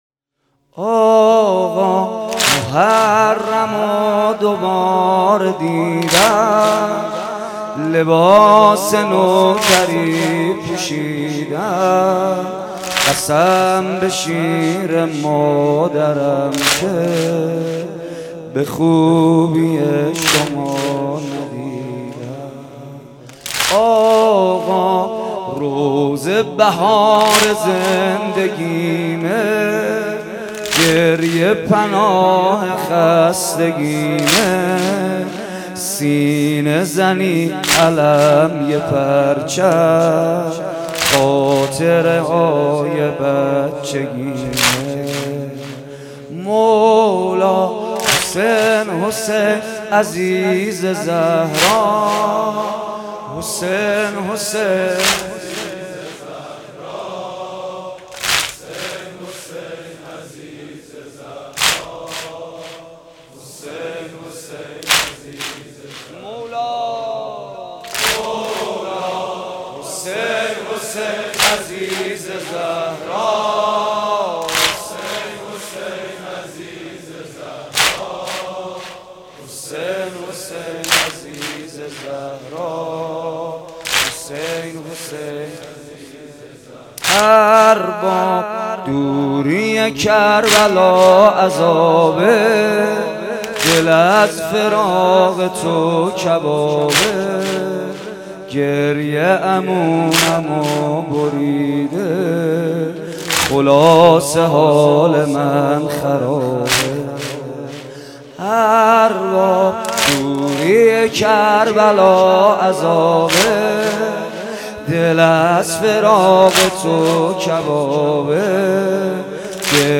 شب چهارم محرم95 /هیئت ریحانه النبی(س)